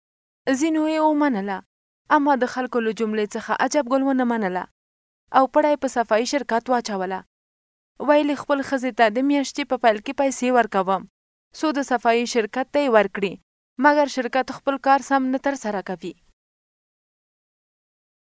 Professional Female Pashto Voice Samples
Our female Pashto voice artists offer a wide range of tones.
FEMALE_PASHTO-4.mp3